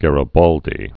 (gărə-bôldē, gärē-bäldē), Giuseppe 1807-1882.